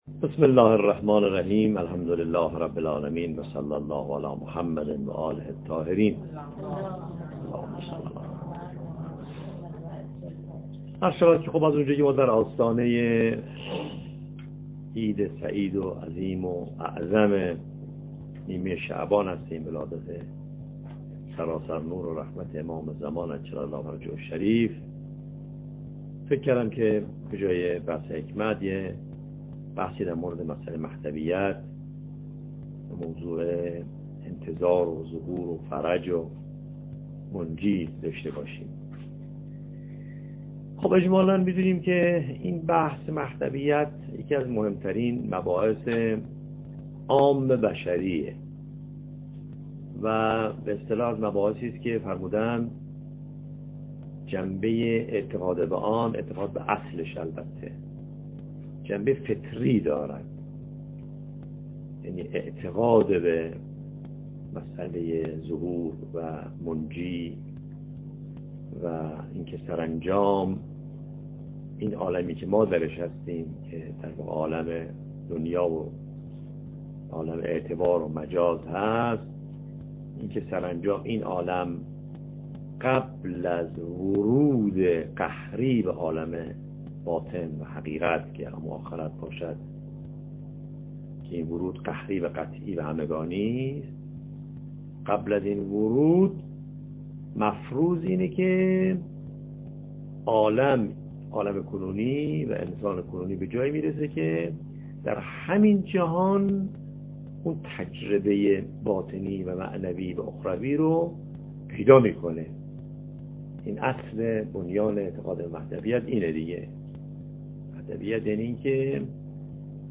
گفتاری در موضوع مهدویت